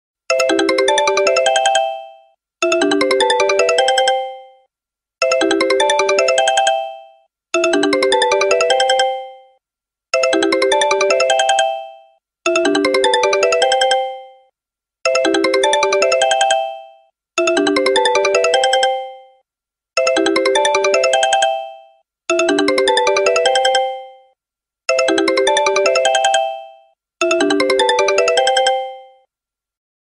📲 Sony Ericsson Classic Klingelton sound effects free download